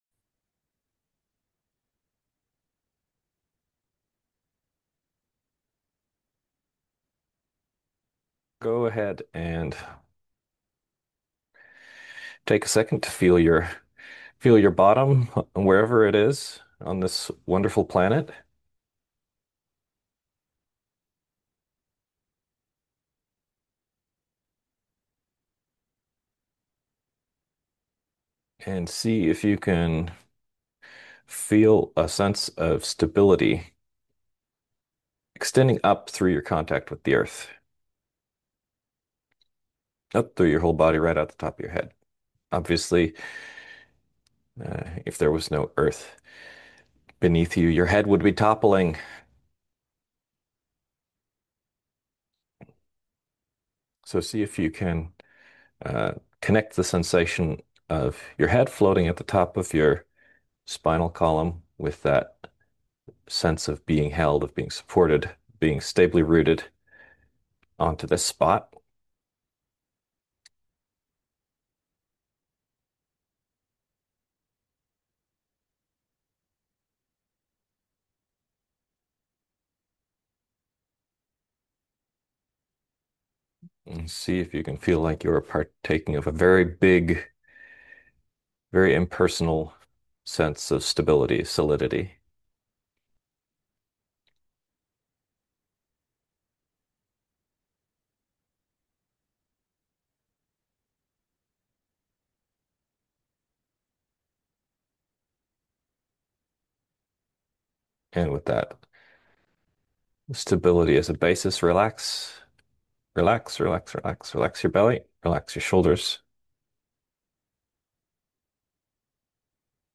Podcast (guided-meditations): Play in new window | Download